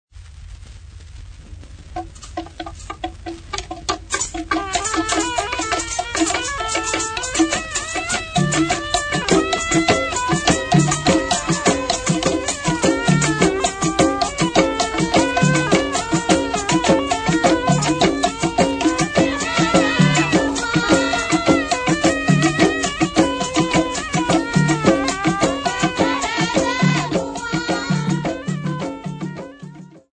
Swahili women
Folk Music
field recordings
sound recording-musical
Indigenous music